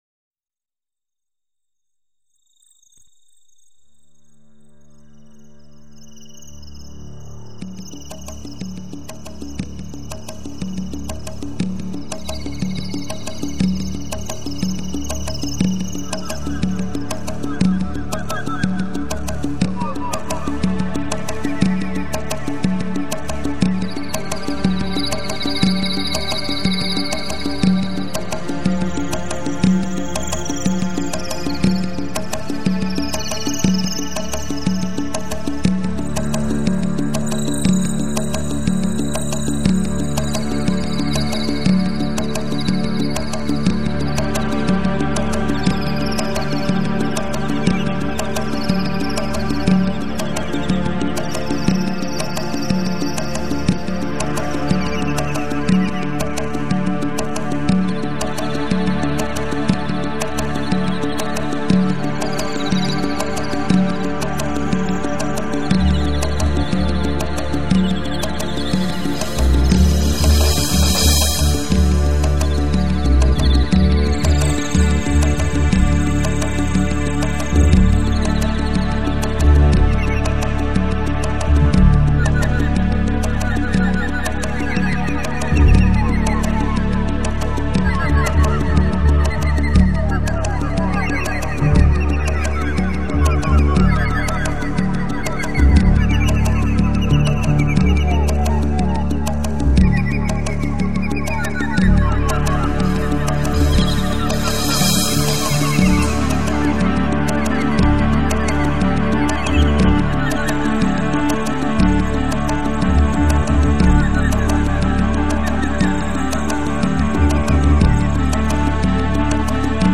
Musiche di scena dall'opera teatrale
Sonorizzazioni dal vivo